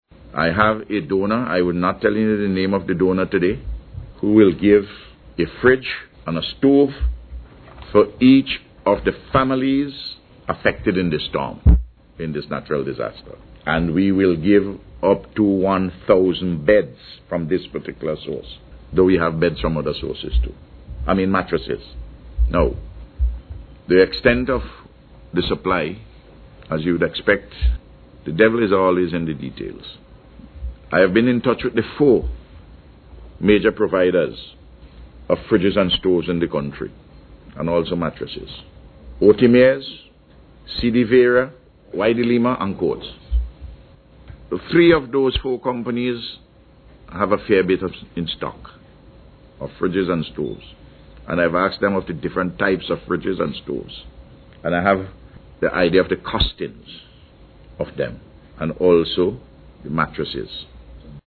The announcement was made by Prime Minister Dr. Ralph Gonsalves during a News Conference yesterday.